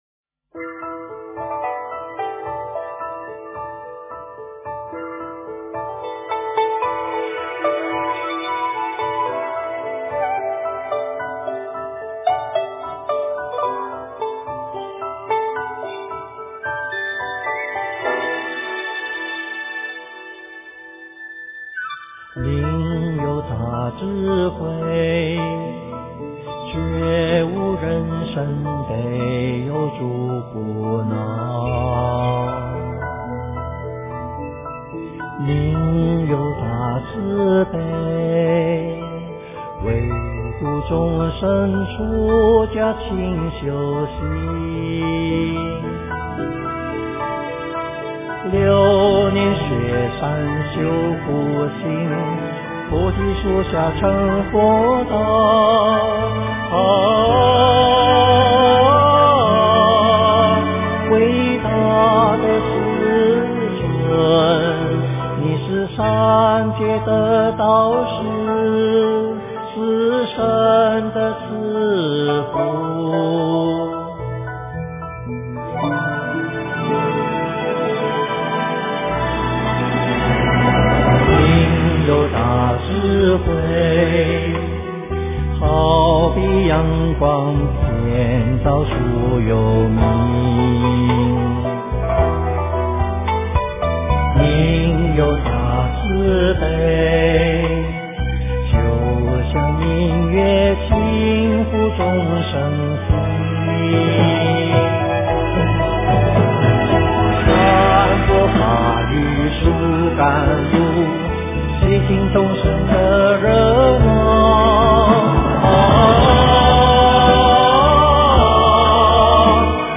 经忏
佛音 经忏 佛教音乐 返回列表 上一篇： 阿弥陀佛赞偈--中峰禅寺 下一篇： 佛宝赞--寺院唱颂版1 相关文章 三稽首--如是我闻 三稽首--如是我闻...